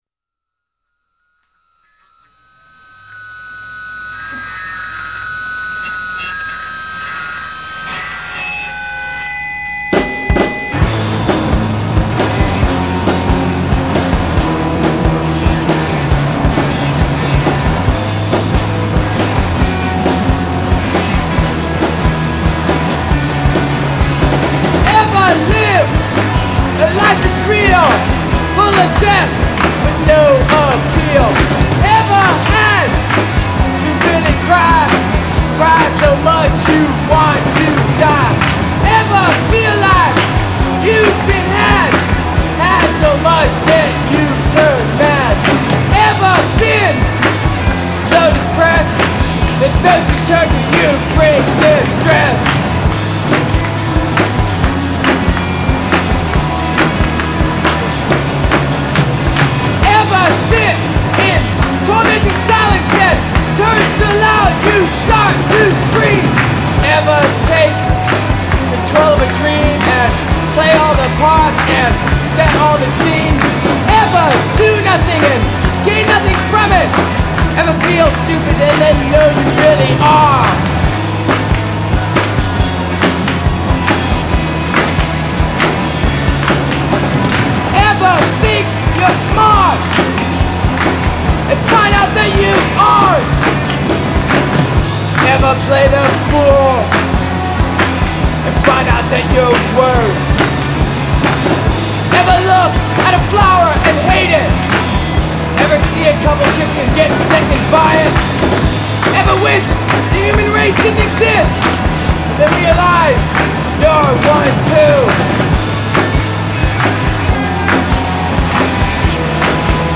A sloppy, grinding monotonous dirge with hardly any lyrics